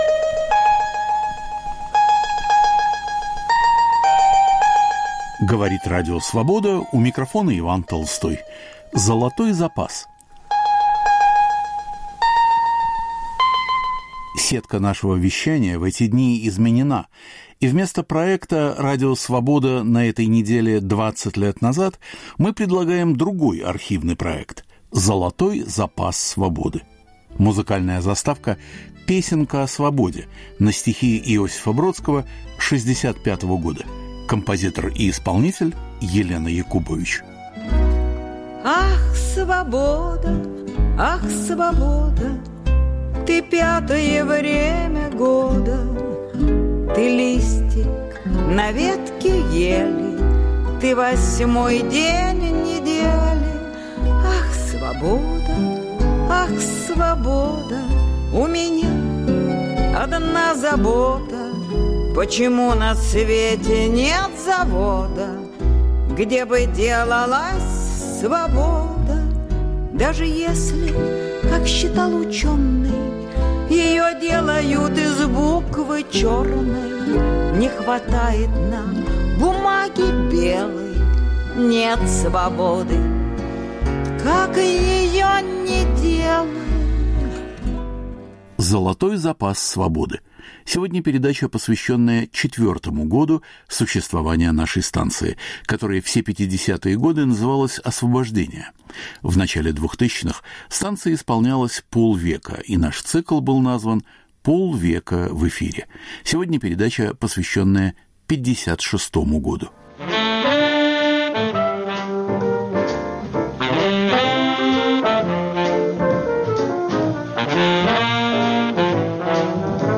Передача из цикла, посвященного 50-летию Радио Свобода. Год 1956 по архивным передачам: XX съезд, мода на русский язык, голливудский фильм "Война и мир".